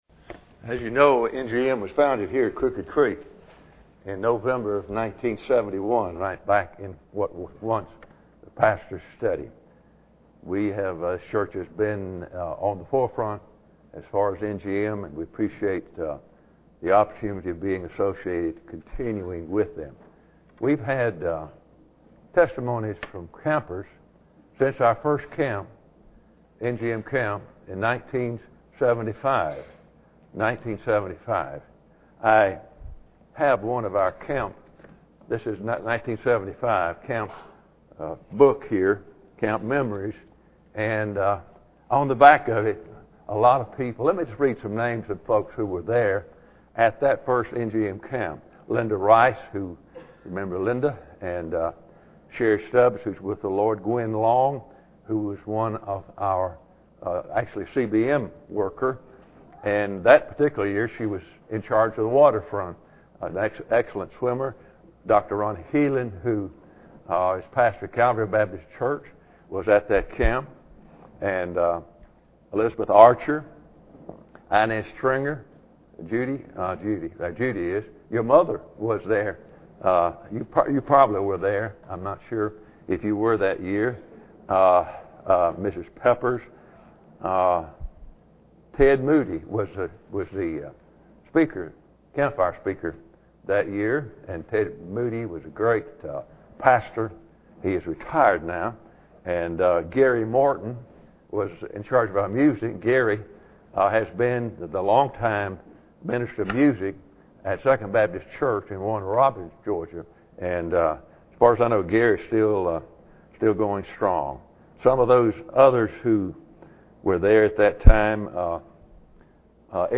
Bible Text: Philippians 1:6 | Preacher: CCBC Members | Series: General
July 10, 2016 NGM 2016 Testimonies Speaker: CCBC Members Series: General Passage: Philippians 1:6 Service Type: Sunday Evening Bible Text: Philippians 1:6 | Preacher: CCBC Members | Series: General « Whatever Happened to SIN?